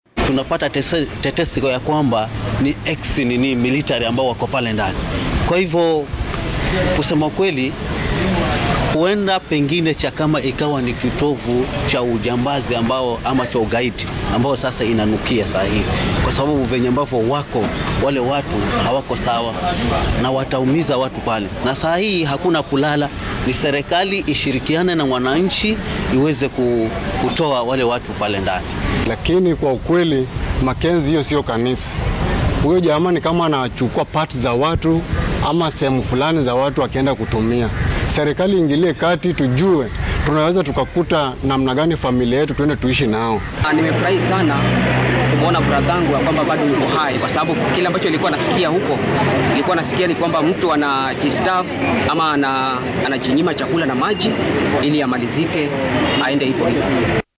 dadka deegaanka oo uu ugu horeeyo wakiilka Adu Ward ayaa ka hadlay dhacdaan.